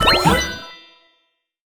pgs/Assets/Audio/Collectibles_Items_Powerup/collect_item_15.wav at master
collect_item_15.wav